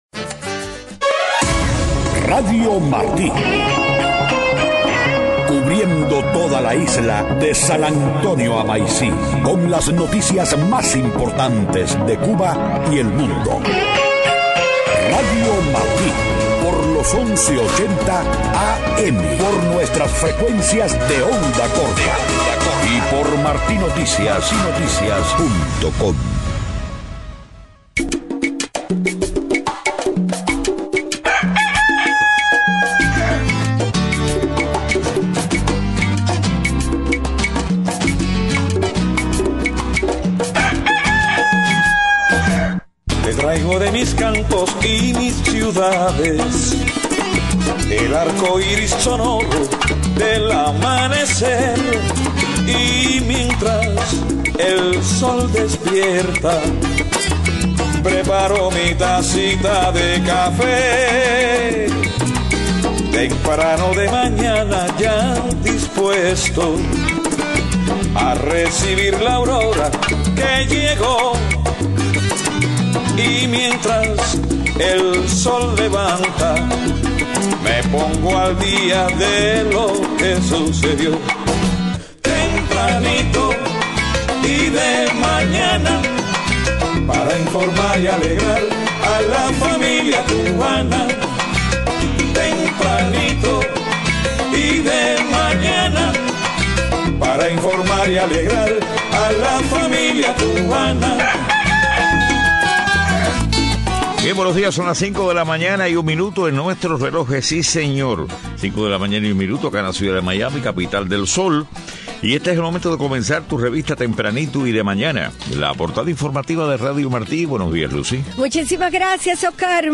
5:00 a.m. Noticias: Otro pelotero cubano decide abandonar la isla en busca de mejor futuro en el extranjero.